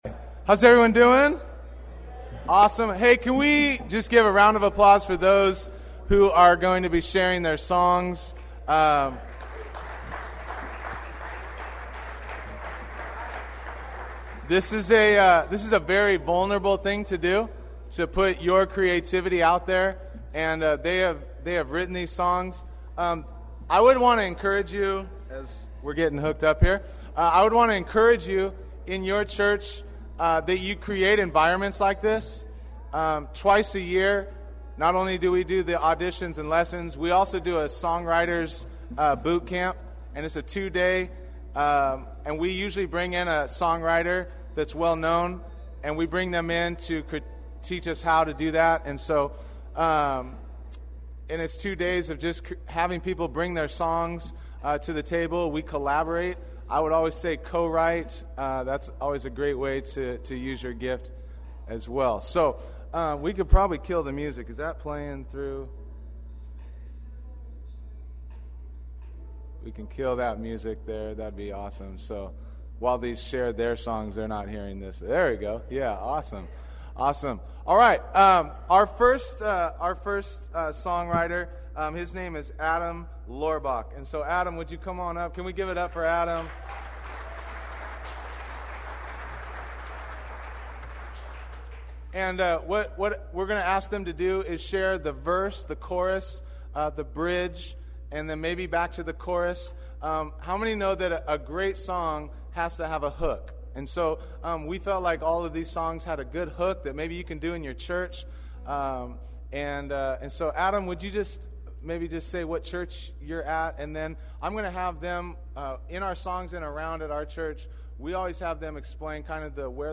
Worship Leaders Panel Discussion (88 minutes)
Recorded live during Foursquare Connection 2012 in Phoenix, this three-part Learning Track features a songwriters session with renowned worship leader Matt Redman, presentations that strengthen the skill level of worship leaders for the local church, and performances of original worship music by Foursquare songwriters.